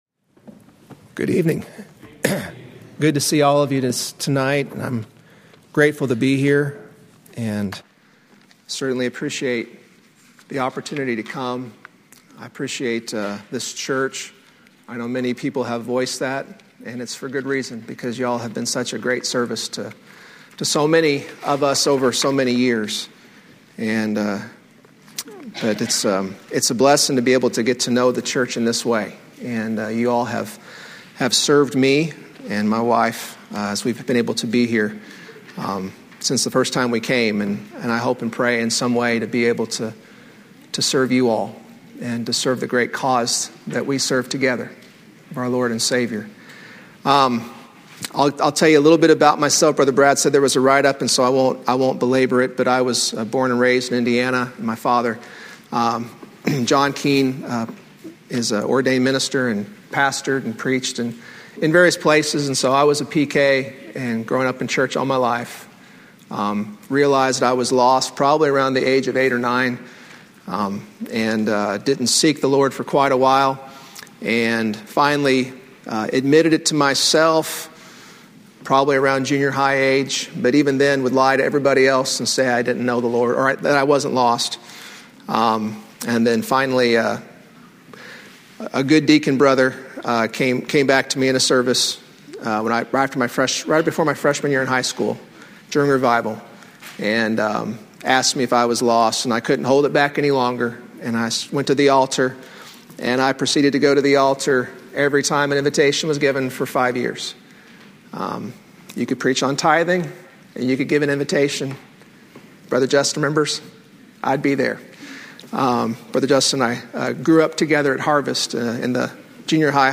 Wednesday night lesson from April 17, 2024 at Old Union Missionary Baptist Church in Bowling Green, Kentucky.